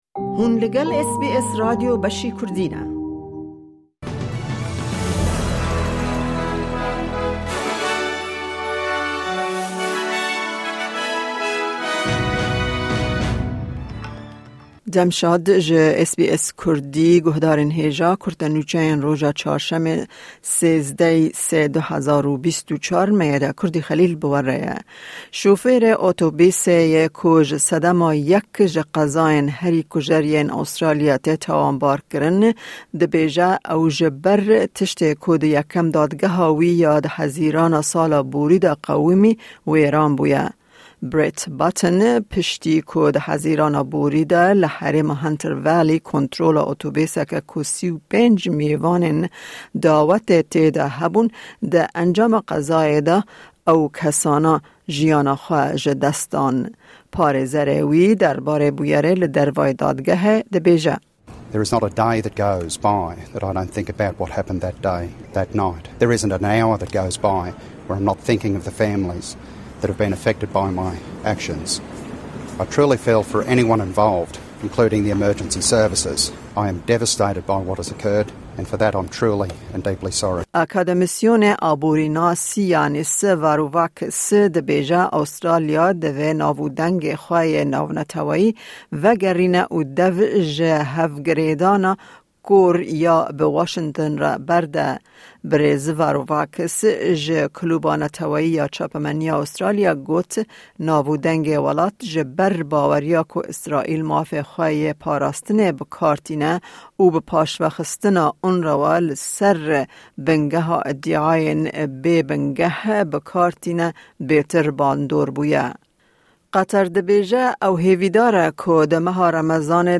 Kurte Nûçeyên roja Çarşemê 13î Adara 2024